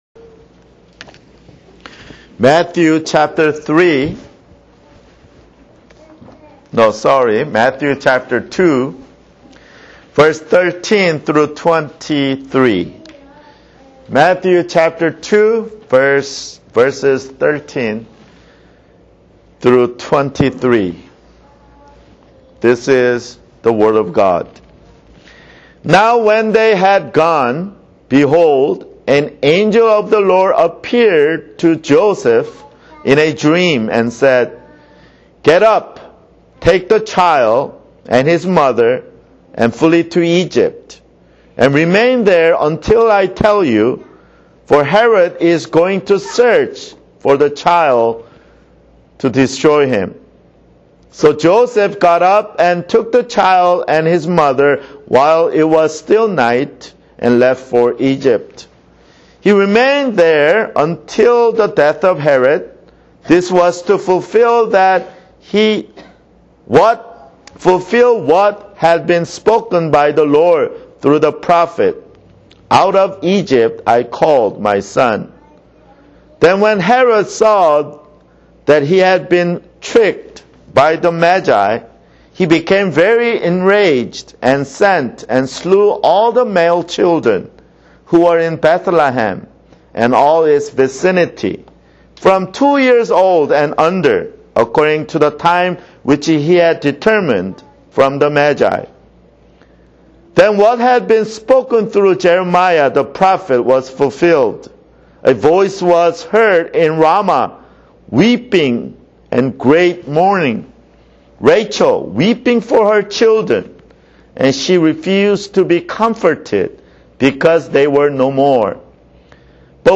[Sermon] Matthew (6)